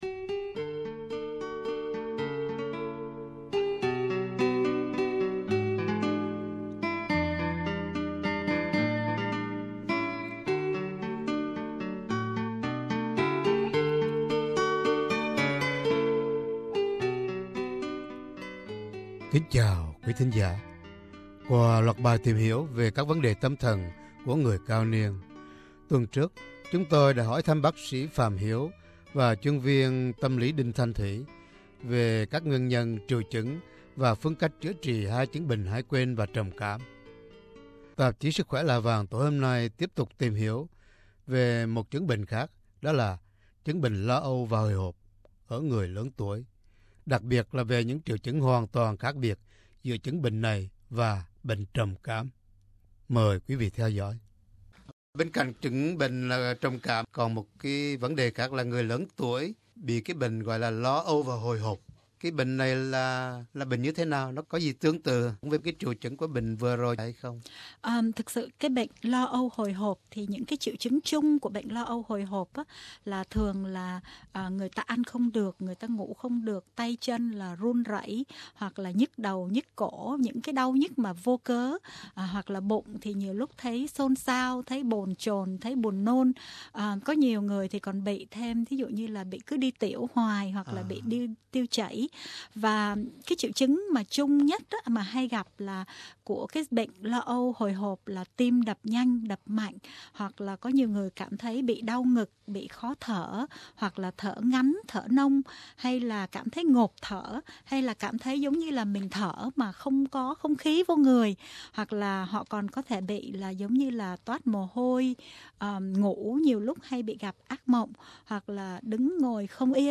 qua buổi mạn đàm